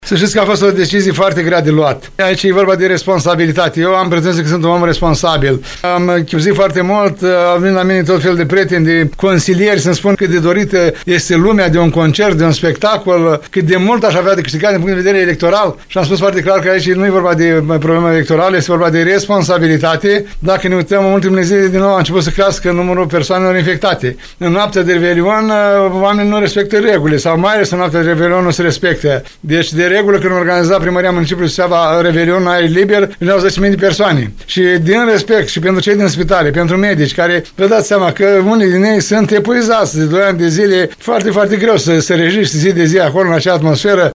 Primarul ION LUNGU a declarat postului nostru de radio că “sucevenii nu vor avea muzică de revelion, dar vor avea sănătate”.